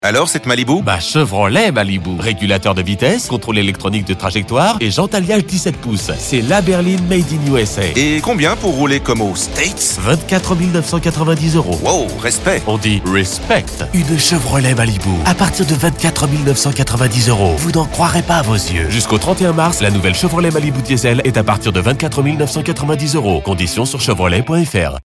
Male
Corporate, Deep, Friendly, Natural, Reassuring
Parisian
Promo.mp3
Microphone: Neumann U87
Audio equipment: cabin